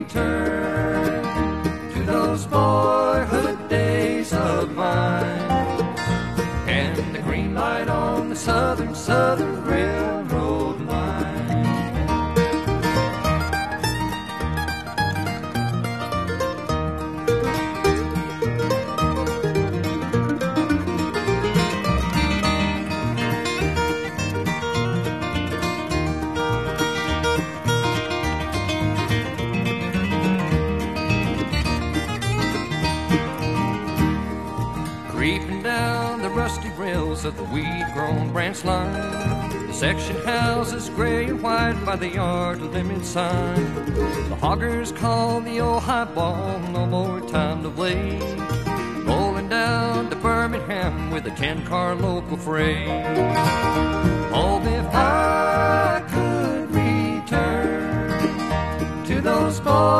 It was hot 4th of July day as Southern 630 pulls its passenger train out of Grand Junction.